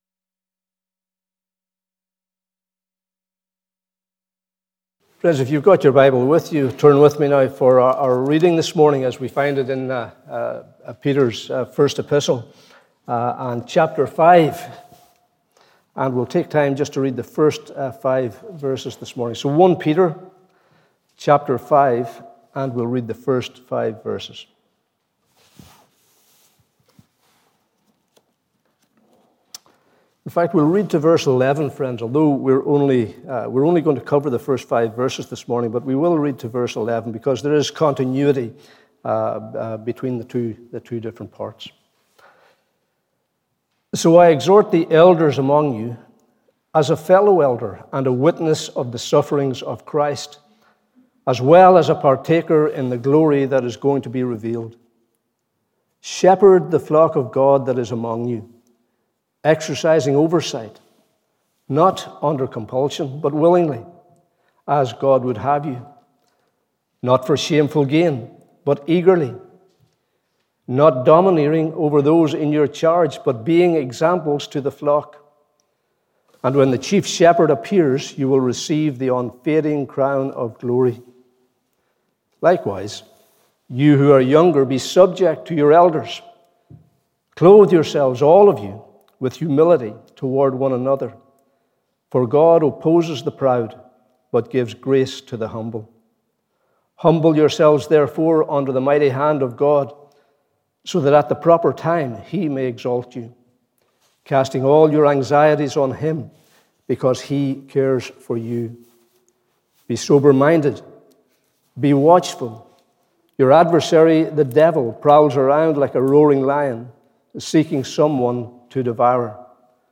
Morning Service 19th June 2022